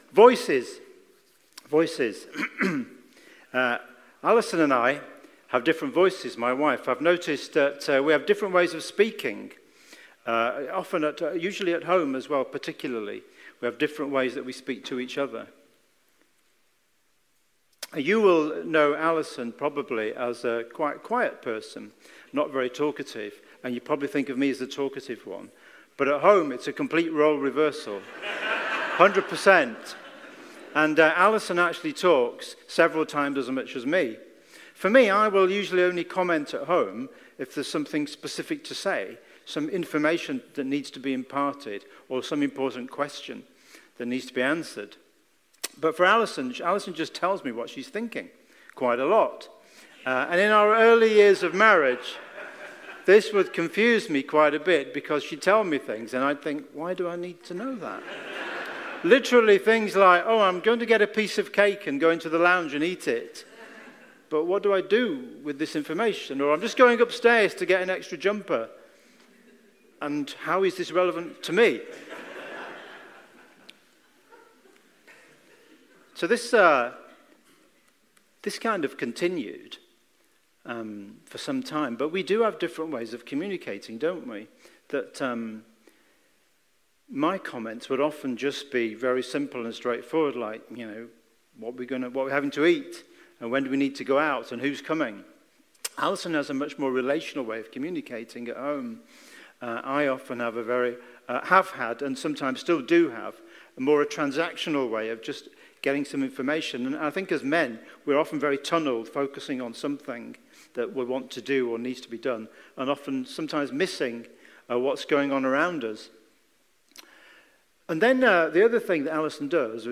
Passage: Luke 2:8-14 Service Type: Sunday Morning